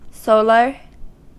Ääntäminen
IPA : /ˈsoʊlə(ɹ)/